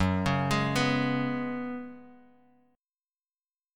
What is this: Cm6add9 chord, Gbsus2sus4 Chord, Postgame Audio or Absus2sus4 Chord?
Gbsus2sus4 Chord